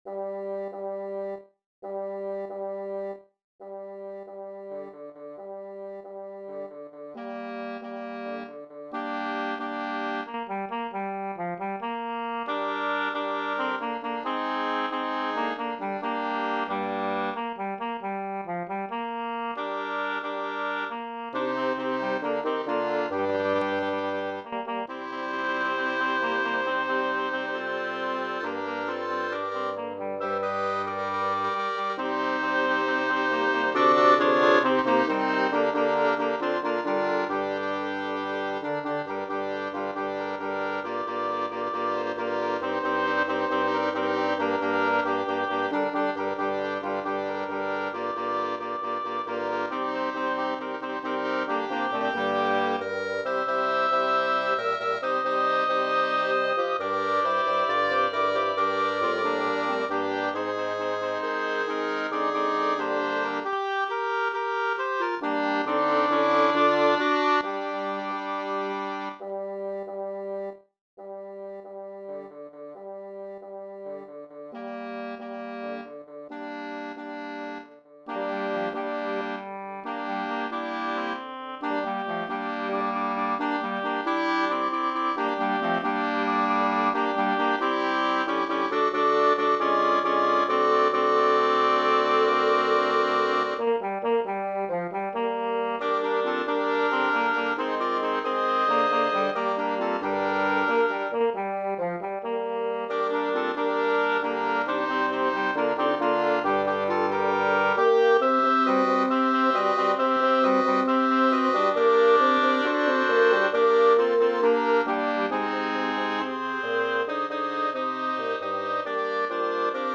SAB / SATB - Folk Songs
Didn't My Lord Deliver Daniel (SAATTB) -
recording (instrumental)